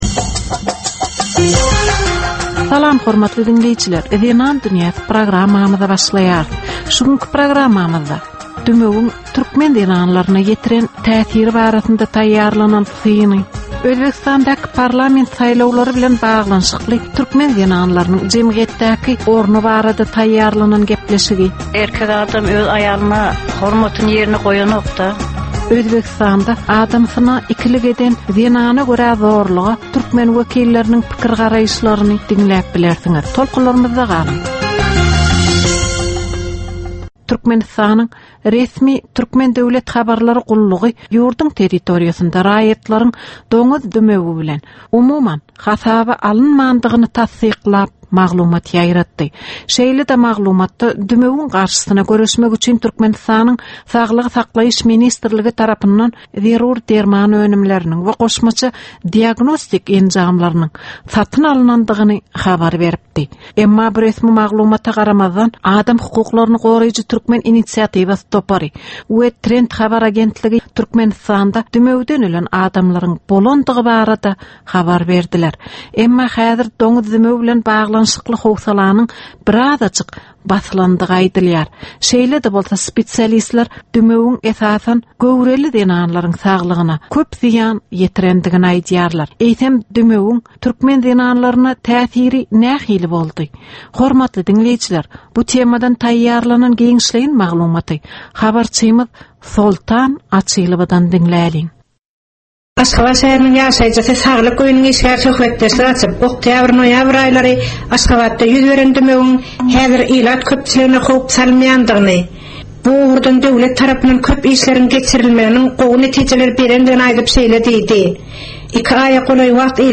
Türkmen we halkara aýal-gyzlarynyň durmuşyna degişli derwaýys meselelere we täzeliklere bagyşlanylyp taýýarlanylýan 15 minutlyk ýörite gepleşik. Bu gepleşikde aýal-gyzlaryň durmuşyna degişli maglumatlar, synlar, bu meseleler boýunça synçylaryň we bilermenleriň pikrileri, teklipleri we diskussiýalary berilýär.